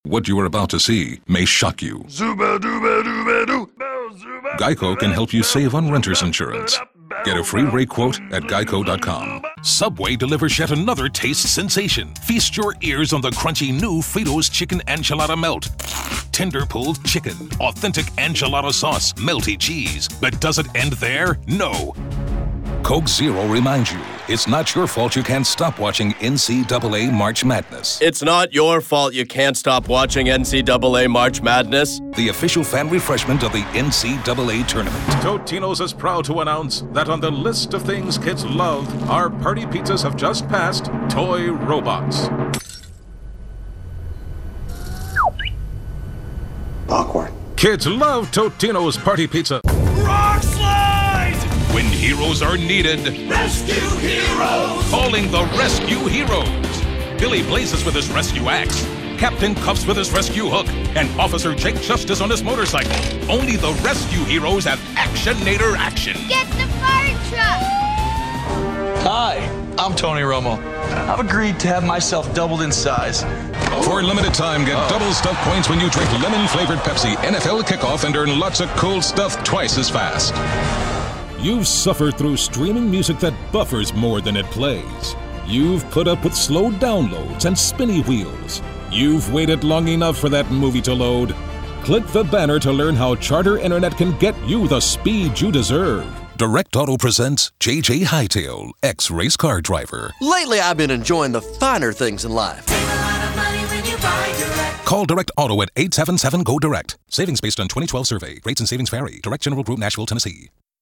Promo Reel